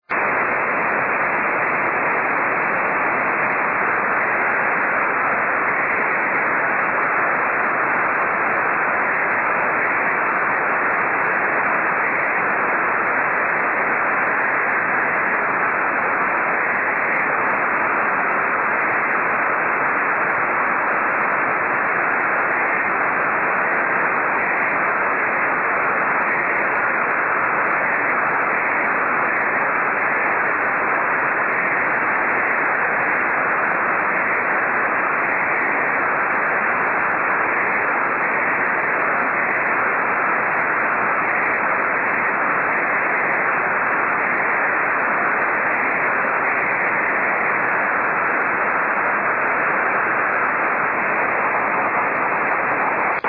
SSB: / WSJT JT65C　Rain scatter